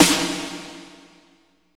49.09 SNR.wav